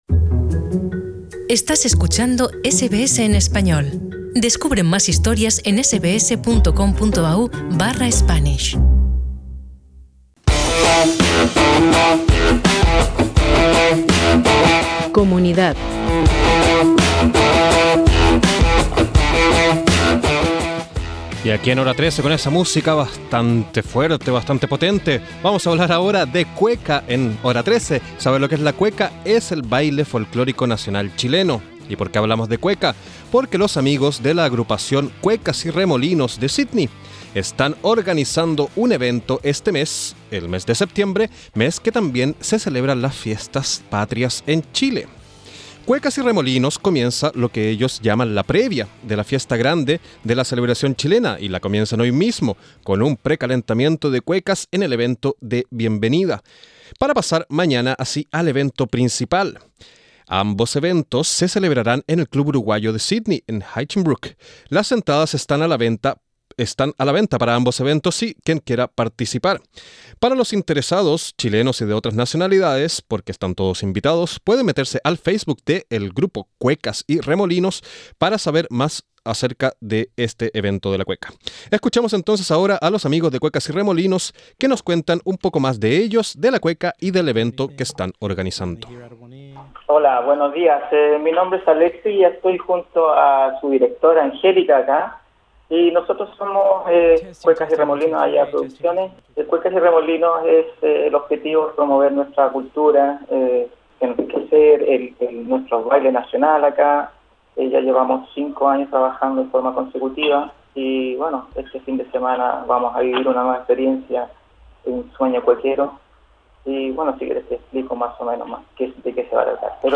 Escucha a los amigos de Cuecas y Remolinos contándonos un poco más de ellos, de la cueca y del evento que están organizando.